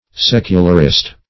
Secularist \Sec"u*lar*ist\, n.